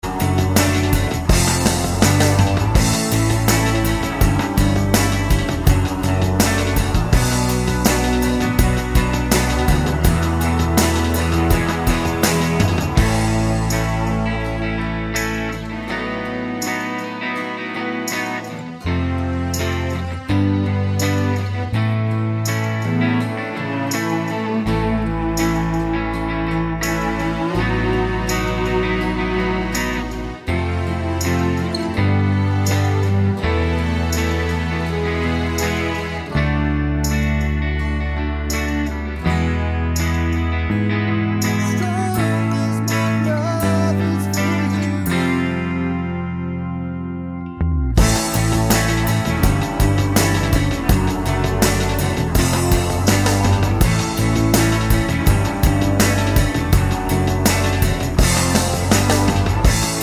no Backing Vocals Rock 4:03 Buy £1.50